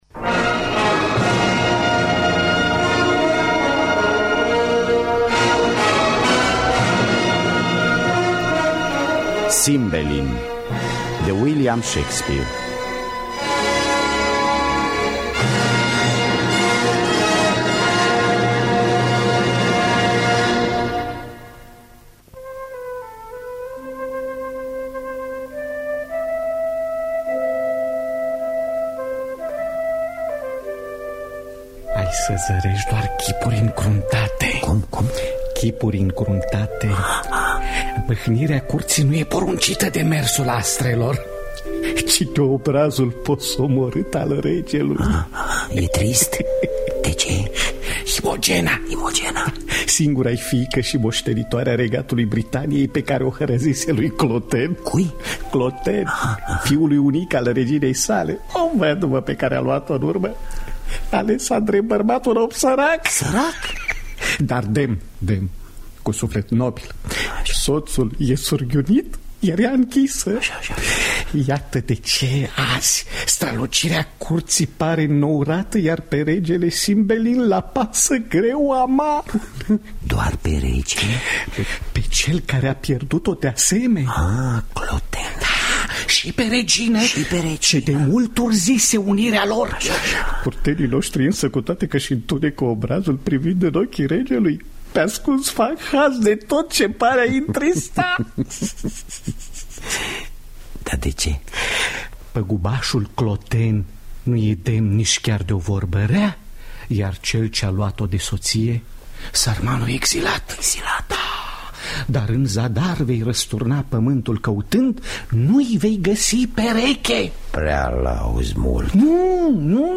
Adaptarea şi traducerea radiofonică